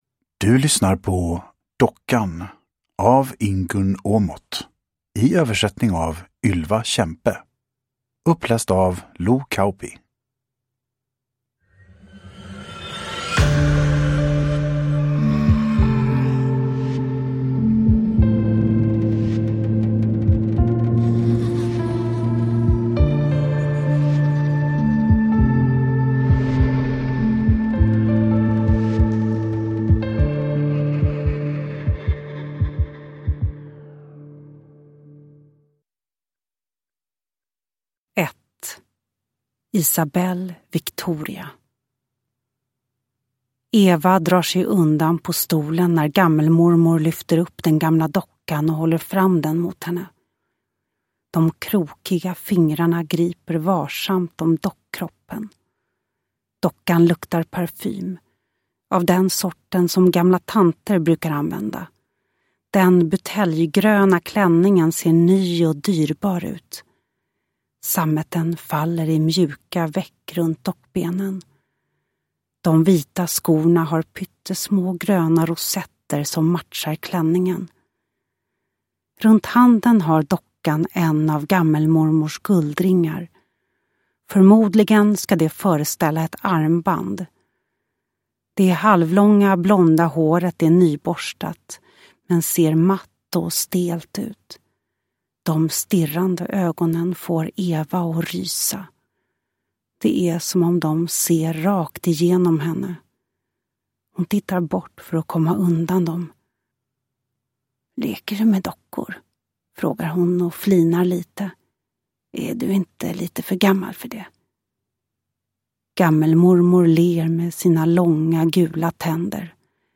Dockan – Ljudbok – Laddas ner
Uppläsare: Lo Kauppi